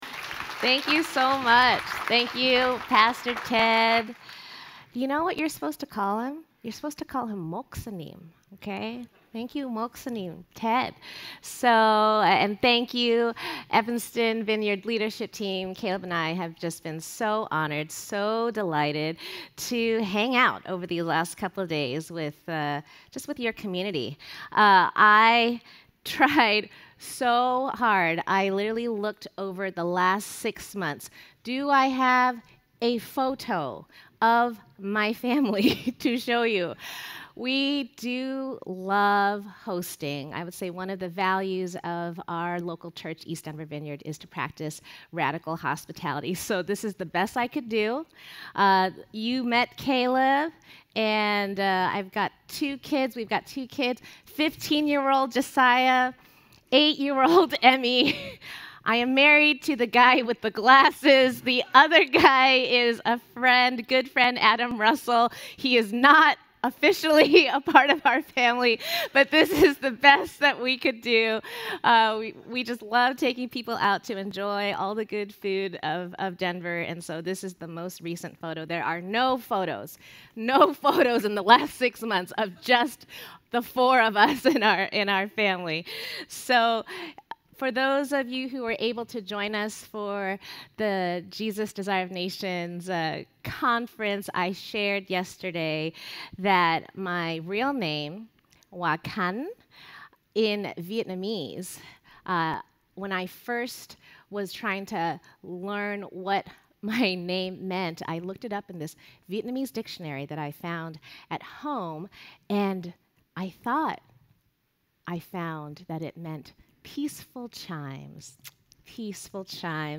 brings this morning's message.